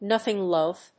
アクセントnóthing lóath